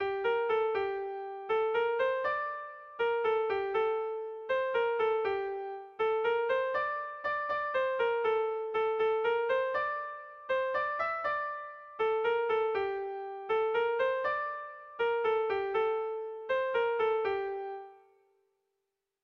Bertso melodies - View details   To know more about this section
Irrizkoa
ABDAB